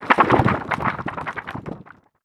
ihob/Assets/Extensions/CartoonGamesSoundEffects/Shake_v1/Shake_v3_wav.wav at master
Shake_v3_wav.wav